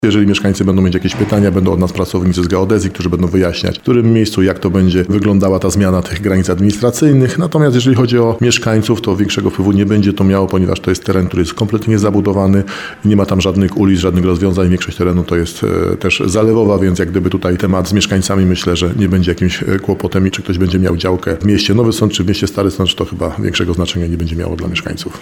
Jak wyjaśnia starosta nowosądecki Tadeusz Zaremba, teraz mieszkańcy mogą wyrazić swoją opinię co do planowanych zmian.